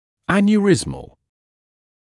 [ˌænjuː’rɪzməl][ˌэнйуː’ризмэл]аневризматический